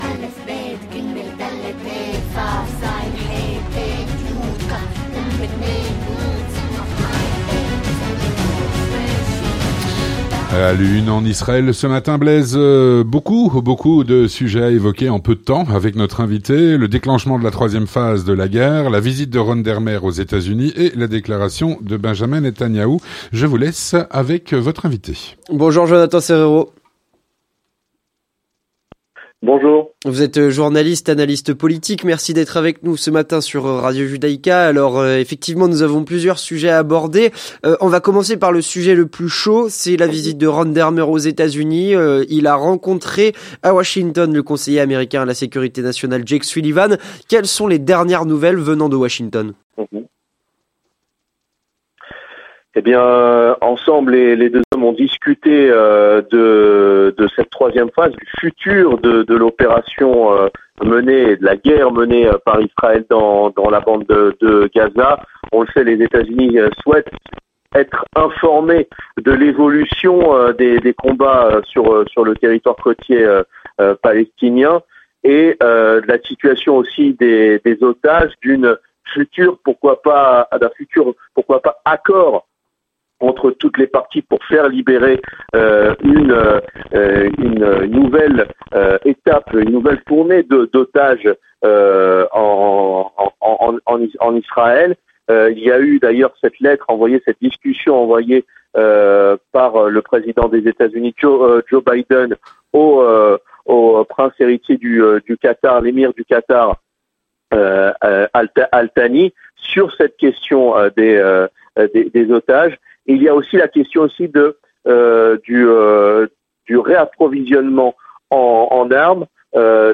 journaliste et analyste politique.